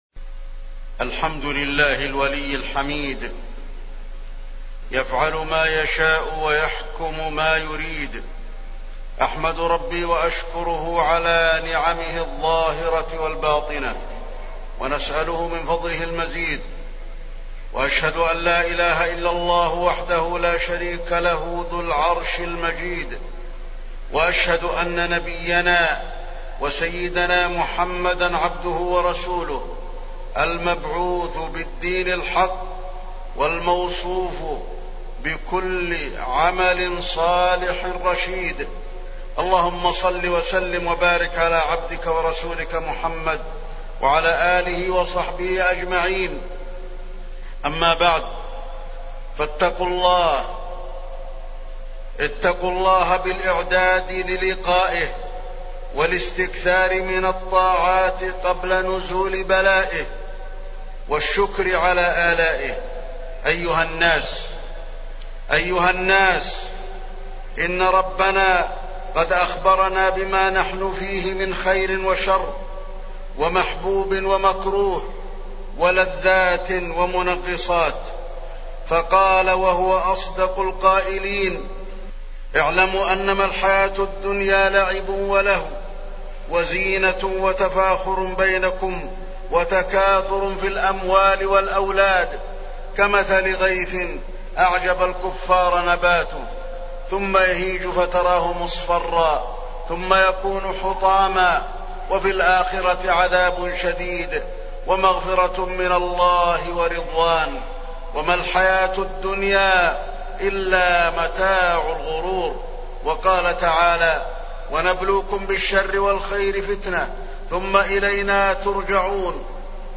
تاريخ النشر ٢ ذو القعدة ١٤٢٩ هـ المكان: المسجد النبوي الشيخ: فضيلة الشيخ د. علي بن عبدالرحمن الحذيفي فضيلة الشيخ د. علي بن عبدالرحمن الحذيفي أشراط الساعة